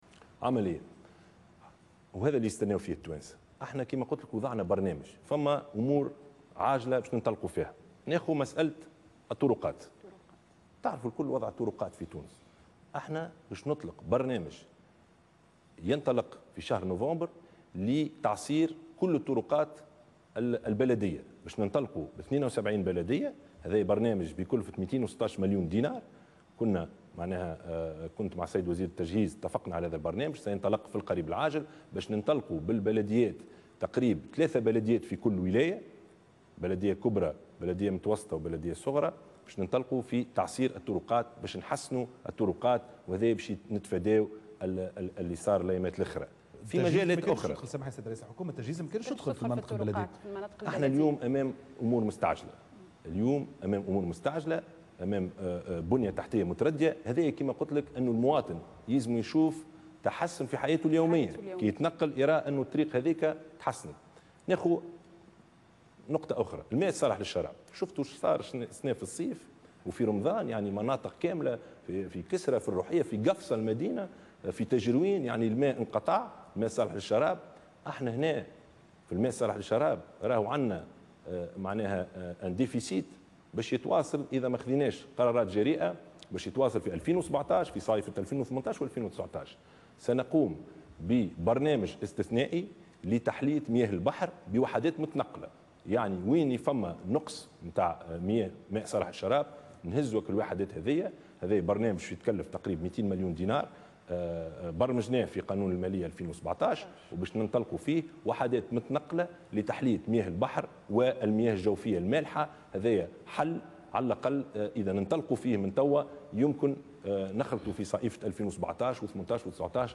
أعلن رئيس الحكومة يوسف الشاهد خلال حوار على القناة الوطنية الأولى مساء اليوم الأربعاء 28 سبتمبر 2016 أنه تم اتخاذ إجراءات جديدة وعاجلة لاعادة ثقة المواطن في الدولة.